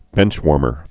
(bĕnchwôrmər)